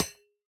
Minecraft Version Minecraft Version 1.21.5 Latest Release | Latest Snapshot 1.21.5 / assets / minecraft / sounds / block / copper_grate / break2.ogg Compare With Compare With Latest Release | Latest Snapshot
break2.ogg